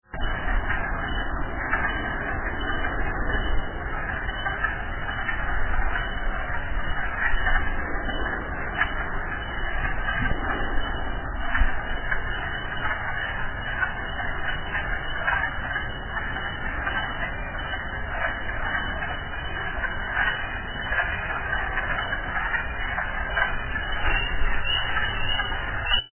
The wood frogs are the first spring chorus.
Here in New England, the first calls of spring often come from Wood Frogs.
All the ice is gone now, and the peepers are out, but it's still early and the wood frogs are calling.
this recording, they're faint and at a distance, but you can still hear them.
Ignore the wind, and the few peepers, you're listening for the wood frogs in the background: they sound like angry ducks squabbling.
woodfrogs.mp3